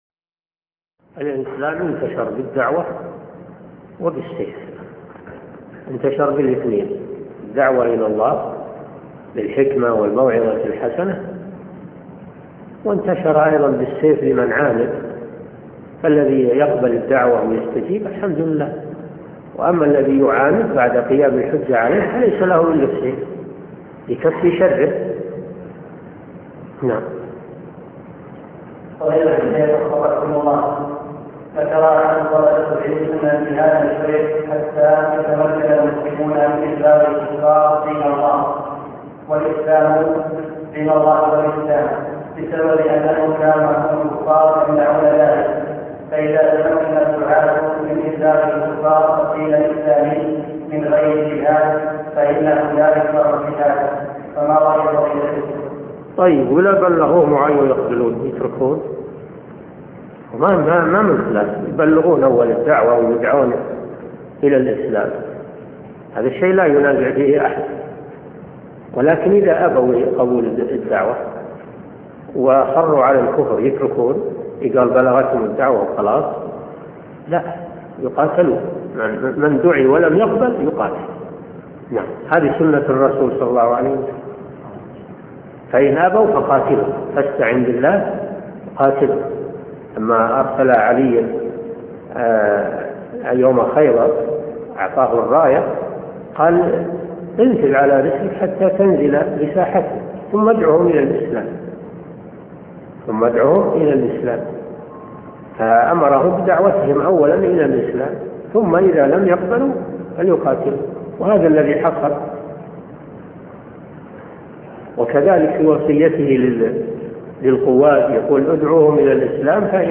عمدة الأحكام في معالم الحلال والحرام عن خير الأنام شرح الشيخ صالح بن فوزان الفوزان الدرس 80